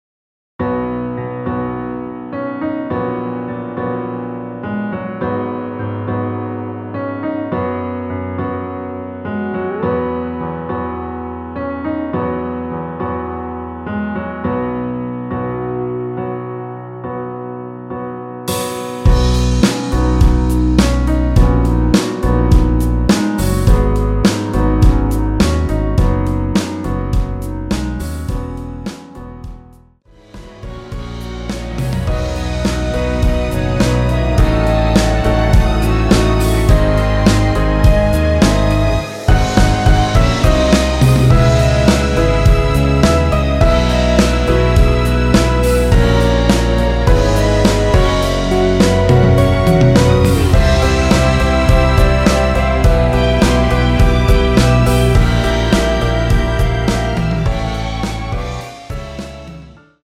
원키에서(-9)내린 멜로디 포함된 MR입니다.(미리듣기 확인)
대부분의 남성분이 부르실 수 있는키로 제작하였습니다.
앞부분30초, 뒷부분30초씩 편집해서 올려 드리고 있습니다.
(멜로디 MR)은 가이드 멜로디가 포함된 MR 입니다.